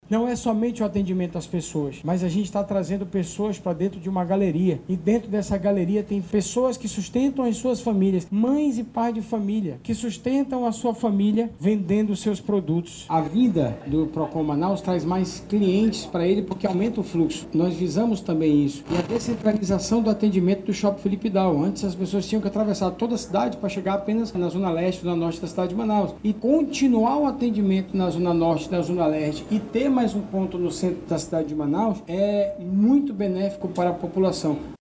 Durante a inauguração, o vice-prefeito de Manaus, Renato Junior, destacou que a nova estrutura, além de descentralizar os serviços, vai atrair mais clientes para os lojistas da galeria.